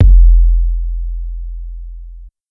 SouthSide Kick Edited (27).wav